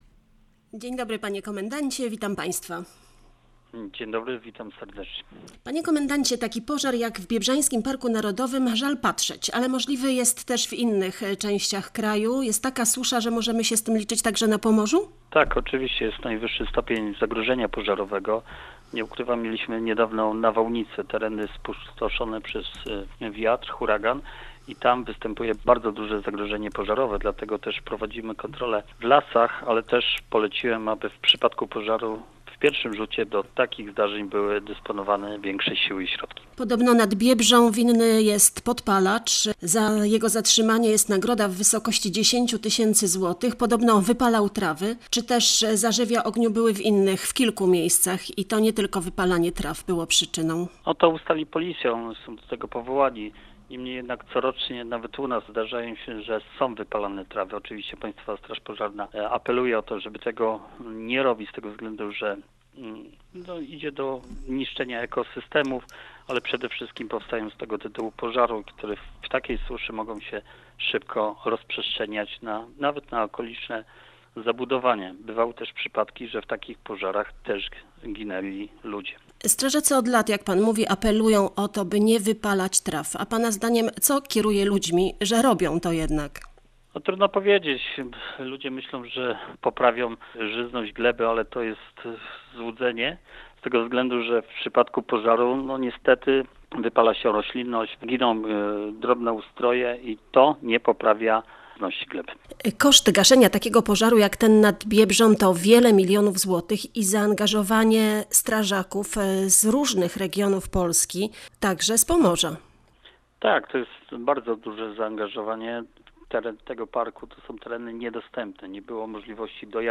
rozmawiała z popołudniowym Gościem dnia Radia Gdańsk, nowym komendantem Wojewódzkiej Straży Pożarnej, st. bryg. Piotrem Sochą.